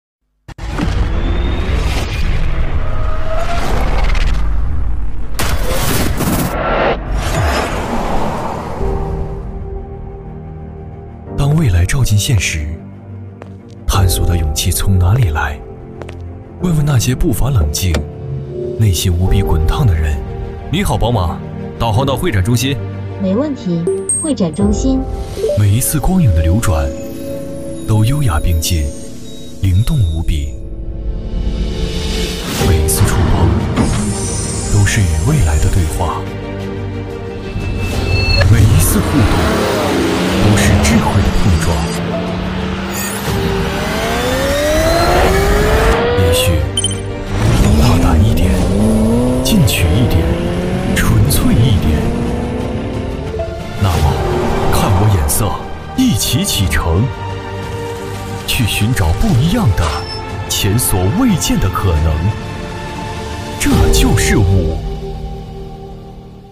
国语配音
男654-TVC广告---宝马5系.mp3